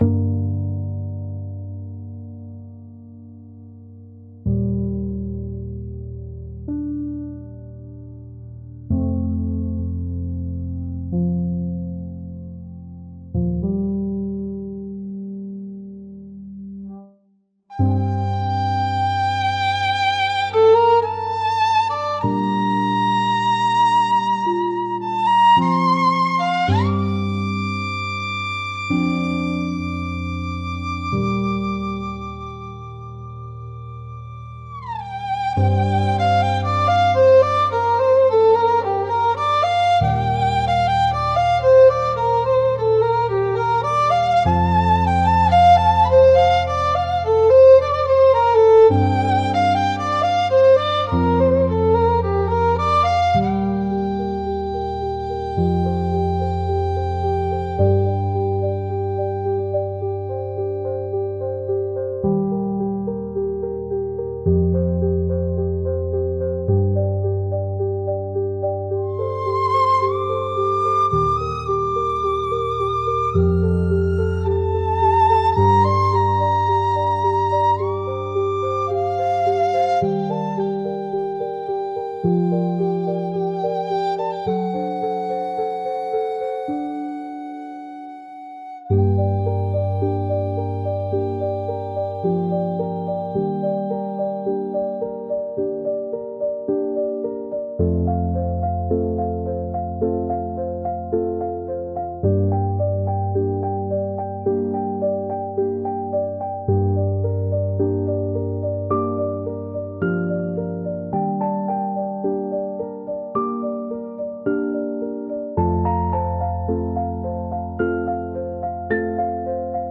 G Minor – 108 BPM
Classical
Electronic
Hip-hop